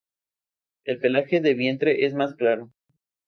Pronounced as (IPA) /ˈklaɾo/